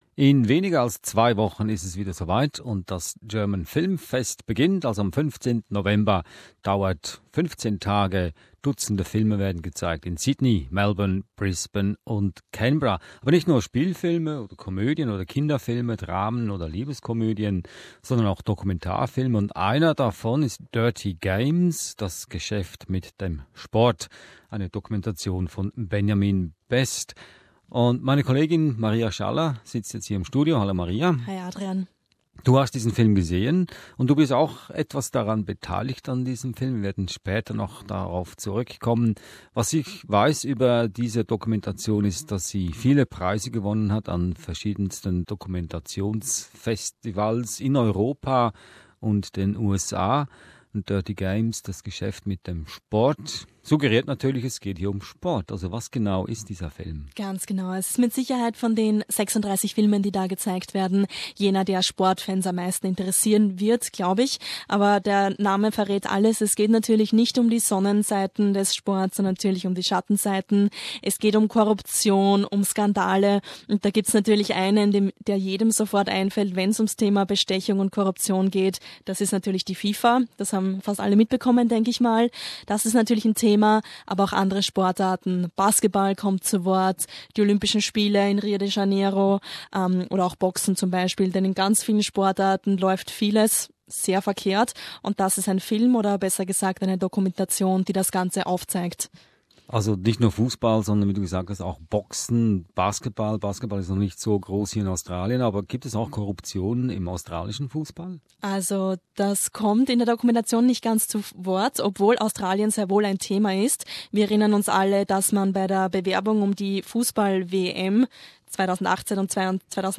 GermanFilmFest review: Dirty Games